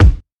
Short Kick Drum One Shot B Key 84.wav
Royality free kick sample tuned to the B note. Loudest frequency: 248Hz
short-kick-drum-one-shot-b-key-84-UOg.mp3